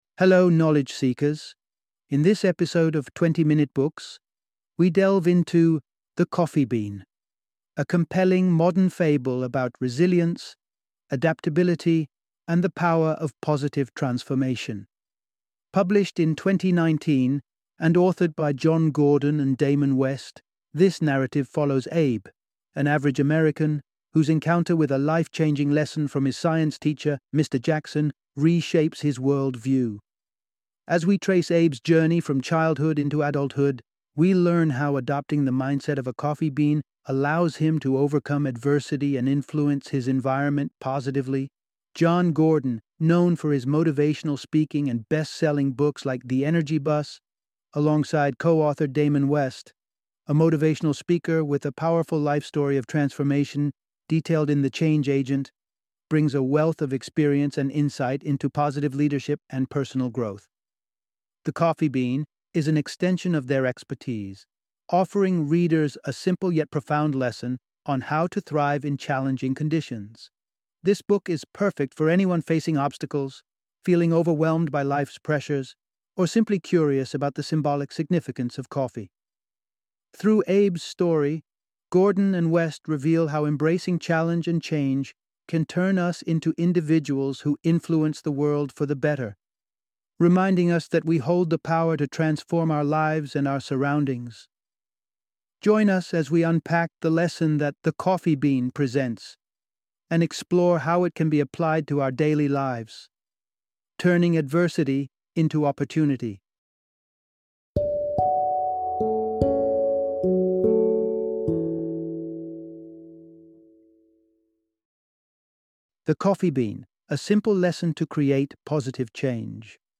The Coffee Bean - Audiobook Summary